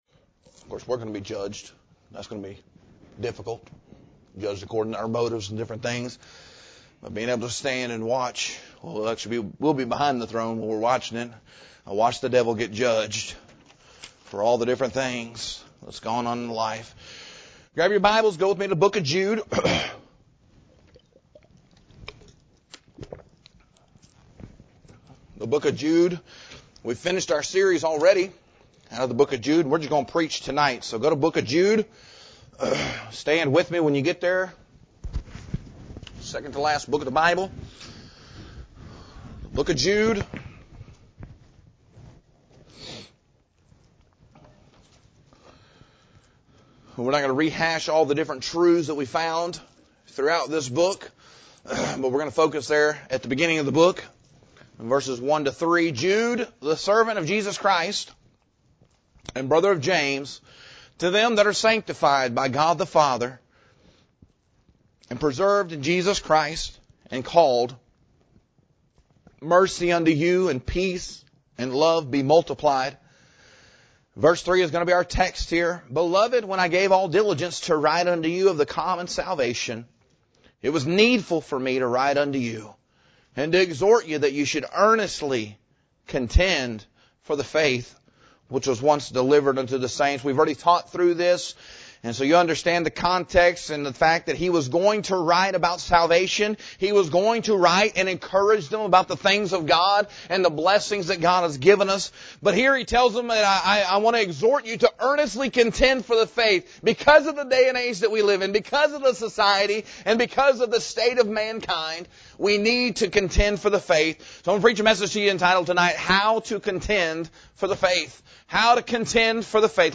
This sermon calls God’s people to stand firm in an age of moral drift, doctrinal compromise, and moral apathy.